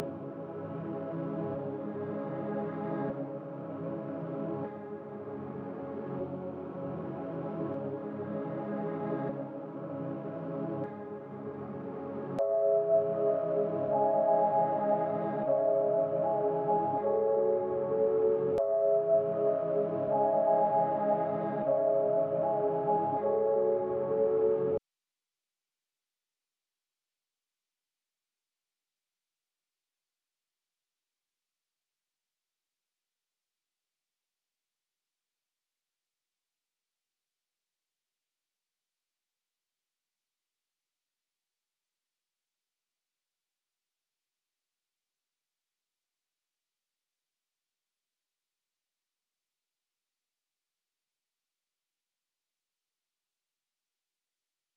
remastered metro sizz 144bpm.wav